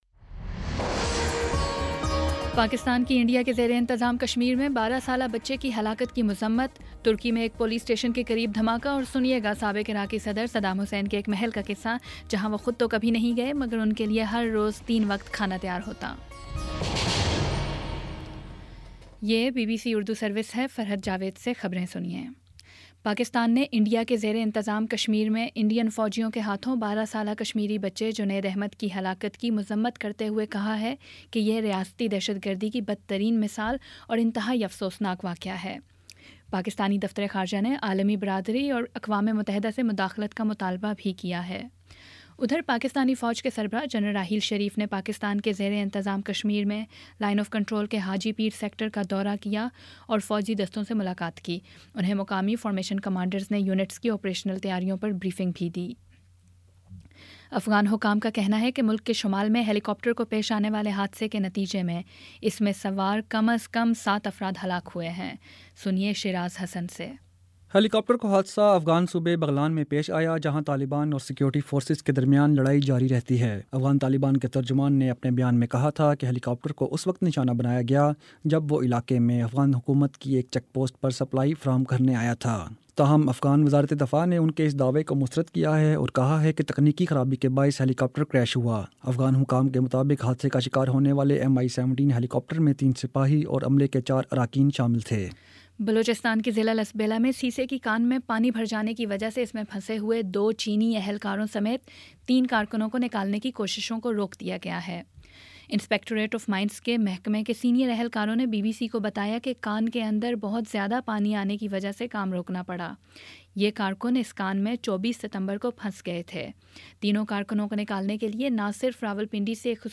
اکتوبر 09 : شام چھ بجے کا نیوز بُلیٹن